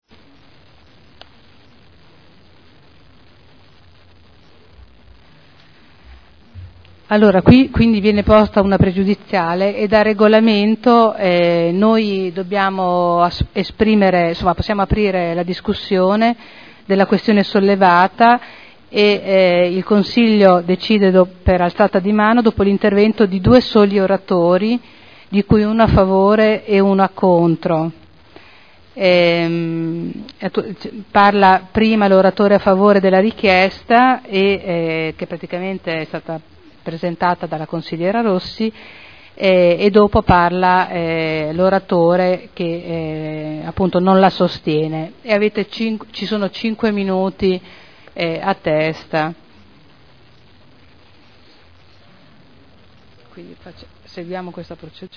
Seduta del 30 gennaio PROPOSTE DI DELIBERAZIONE Rielezione del Collegio dei Revisori dei Conti per il triennio 2012-2014 Pregiudiziale di legittimità, dibattito